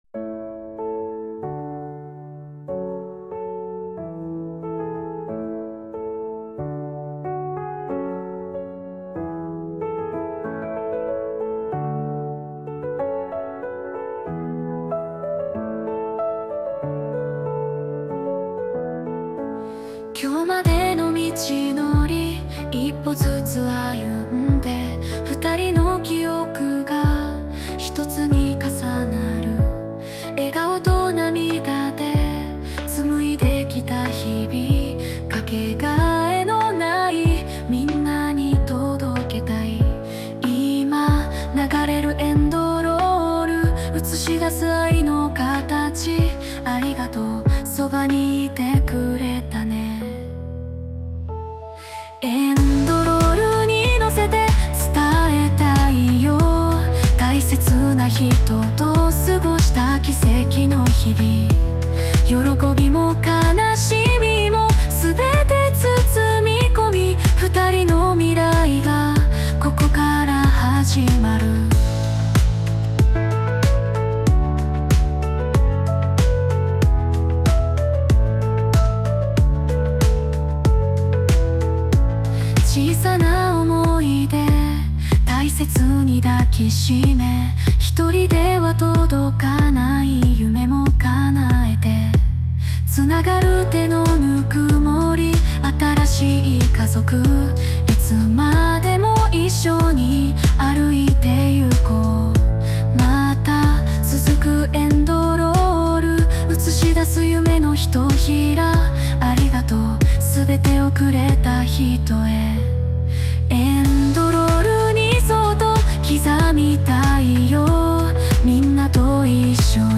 邦楽女性ボーカル著作権フリーBGM ボーカル
著作権フリーオリジナルBGMです。
女性ボーカル（邦楽・日本語）曲です。